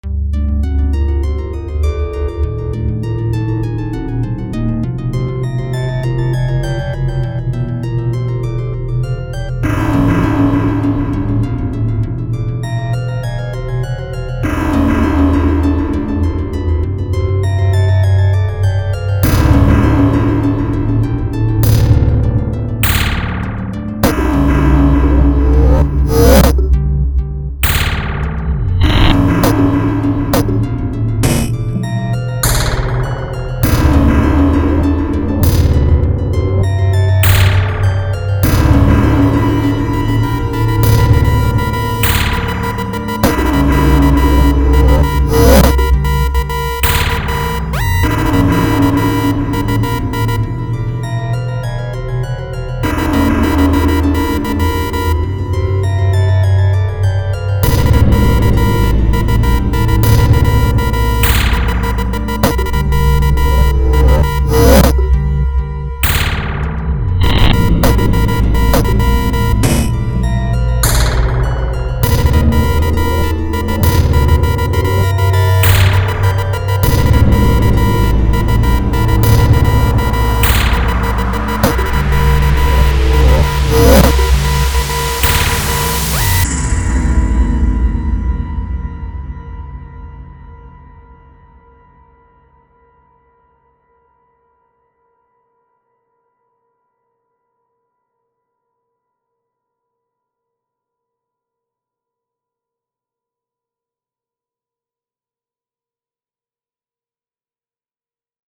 Some atonal dissonant music